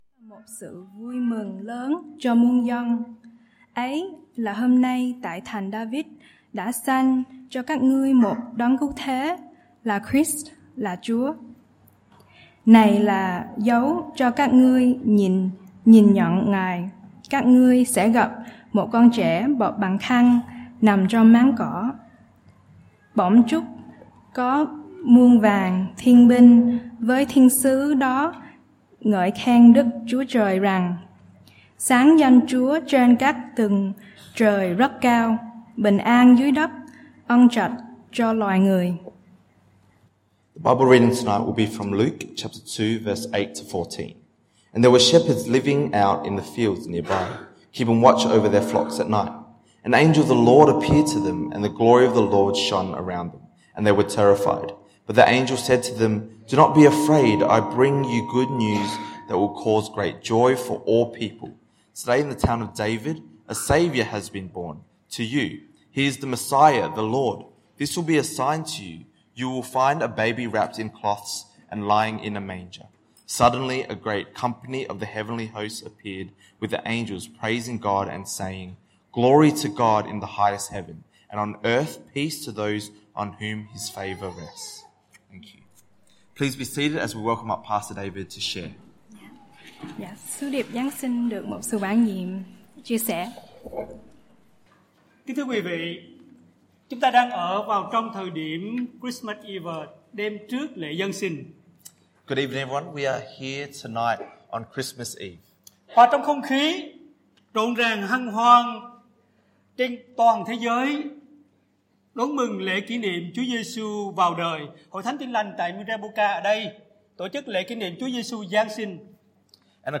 Christmas Eve Service
Bài Giảng